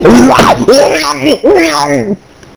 PLAY Zombie Ghost
zombie-ghost.mp3